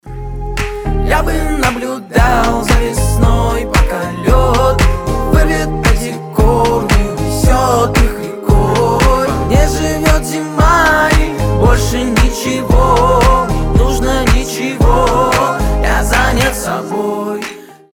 • Качество: 320, Stereo
гитара
ритмичные
теплые
щелчки